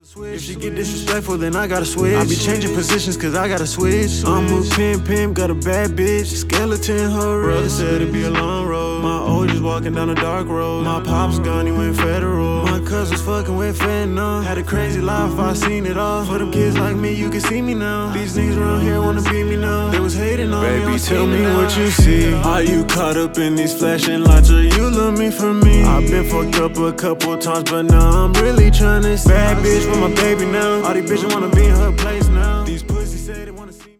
Melodic Rap
MelodicRap.mp3